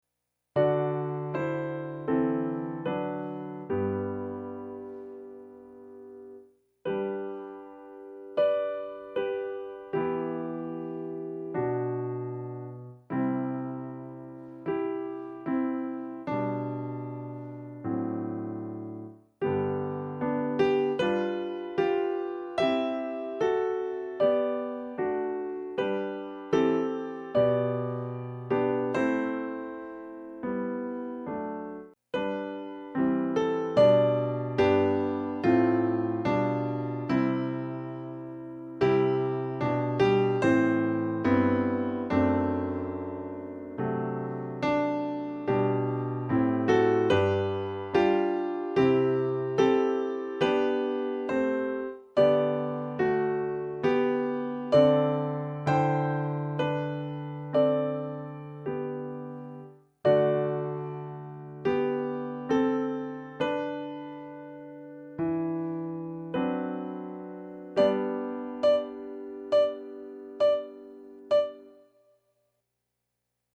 Verse 4 - chorus accomp - MP3